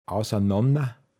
Wortlisten - Pinzgauer Mundart Lexikon